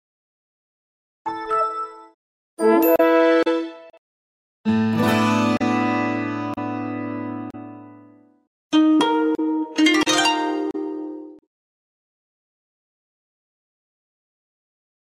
📴Windows 7 Logoff Sound Theme sound effects free download
📴Windows 7 Logoff Sound Theme Comparison📴